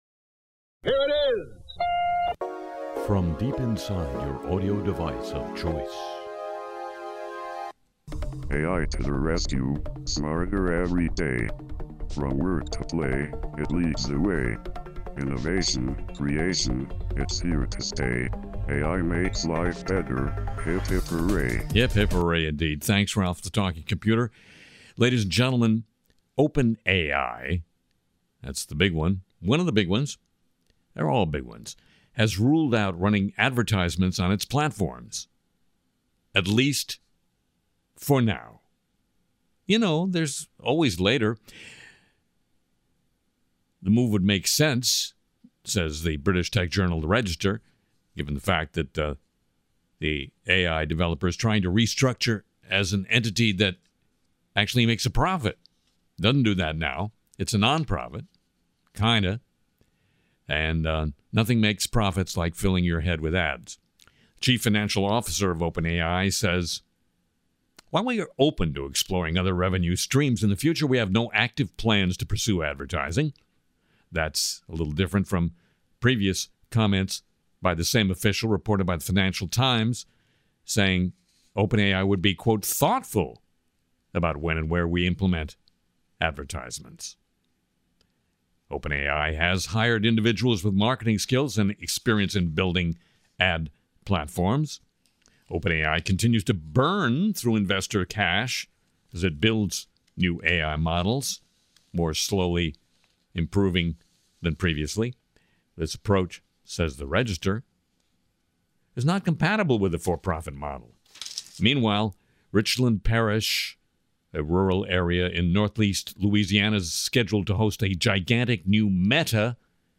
Interview with Dr. Stephanie Kelton, professor of economics and public policy at Stony Brook University